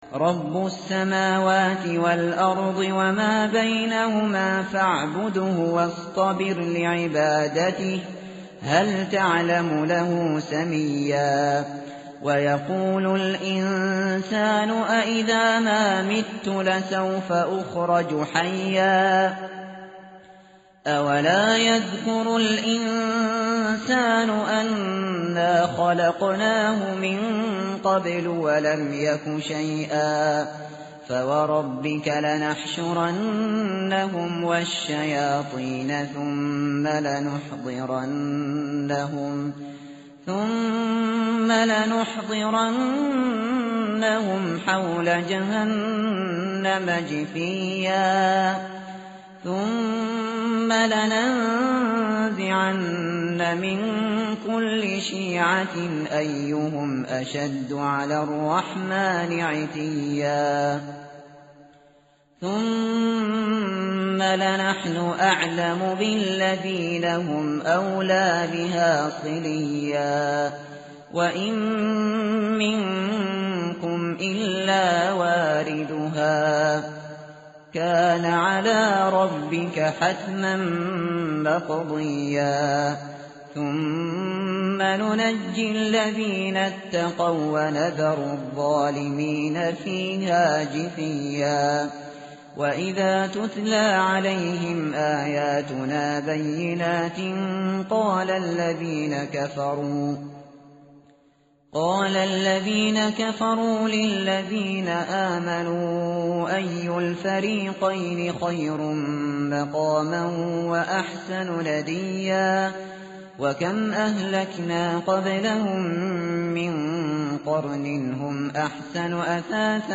tartil_shateri_page_310.mp3